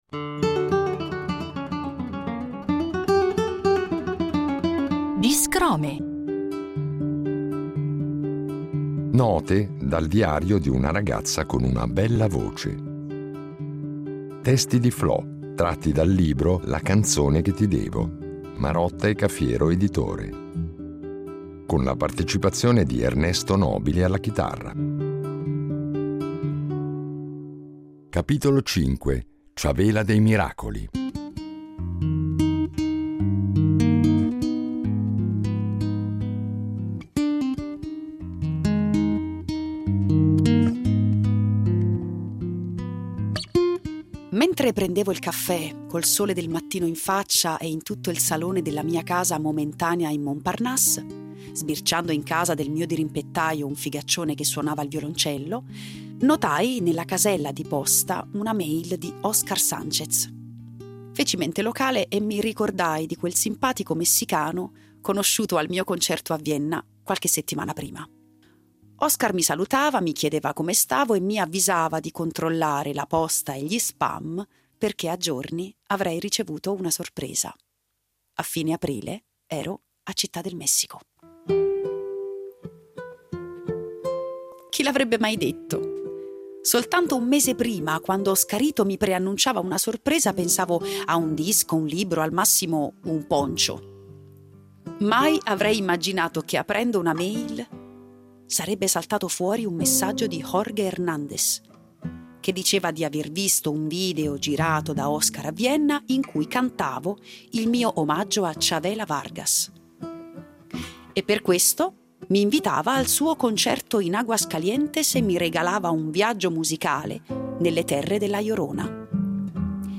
con la voce recitante e cantante
chitarrista